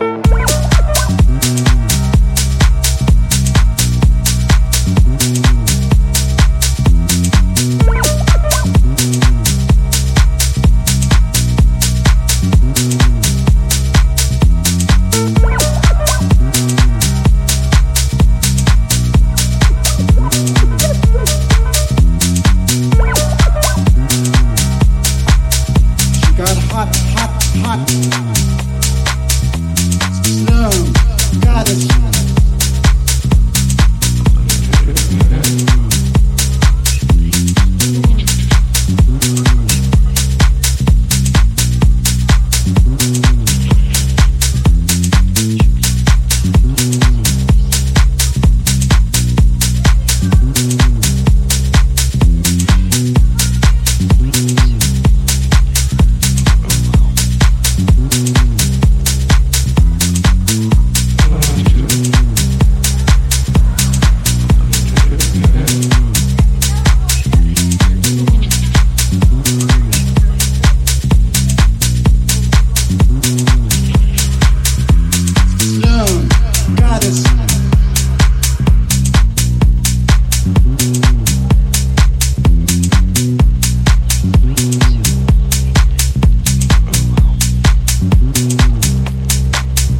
こちらも推進力のあるベースラインを軸に性急なハイハットが牽引する